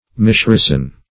Mischristen \Mis*chris"ten\, v. t. To christen wrongly.